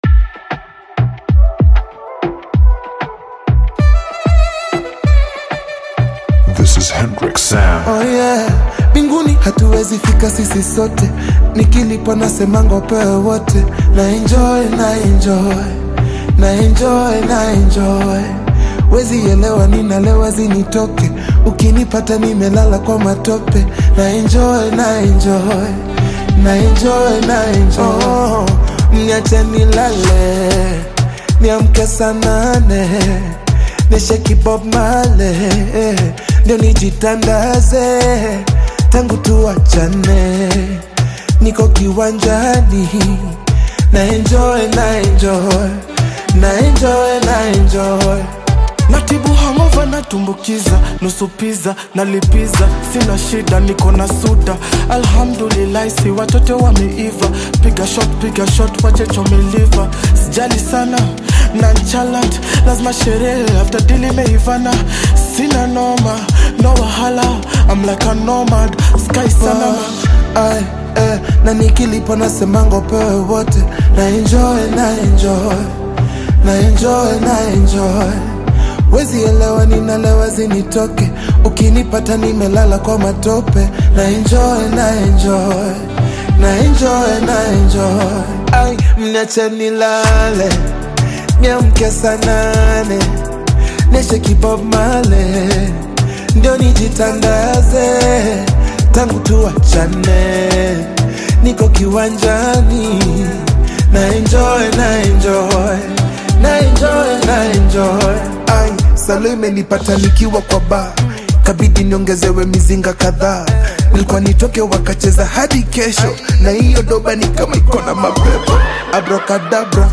powerful vocals
emotive delivery